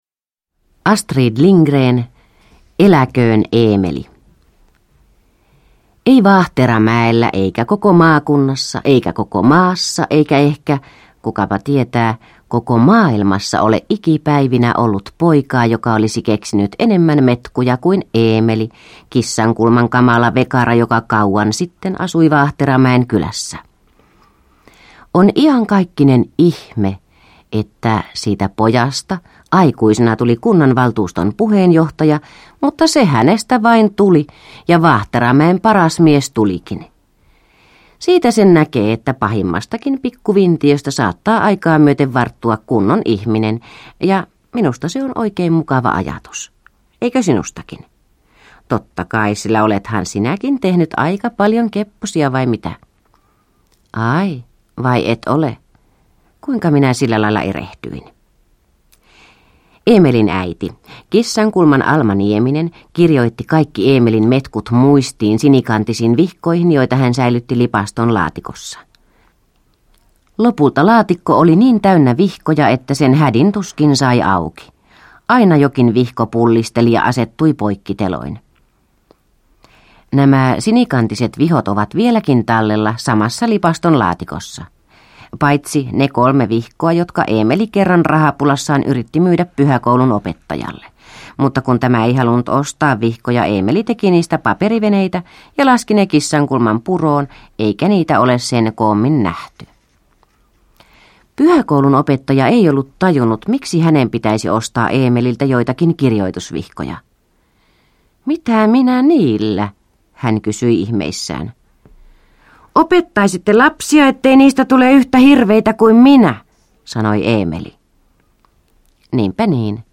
Eläköön Eemeli – Ljudbok – Laddas ner